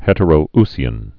(hĕtə-rō-sē-ən, -ousē-ən) also Het·er·ou·si·an (hĕtə-r-, -rou-)